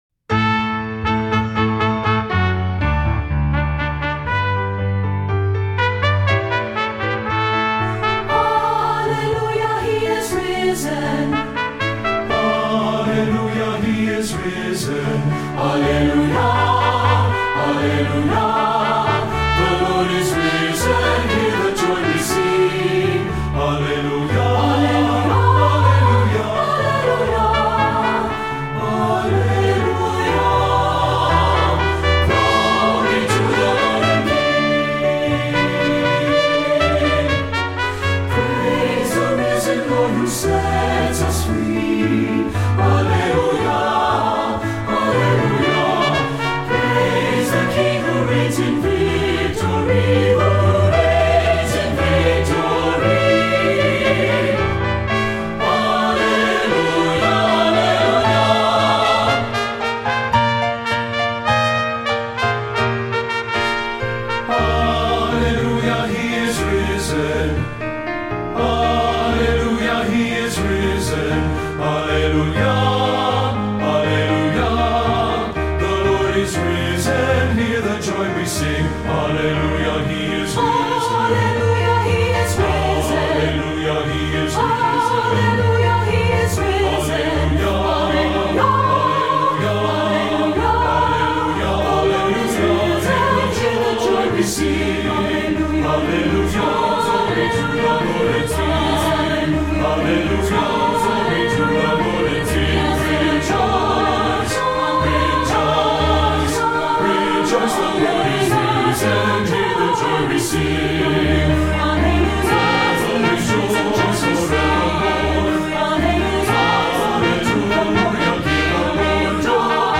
Voicing: SATB and Trumpet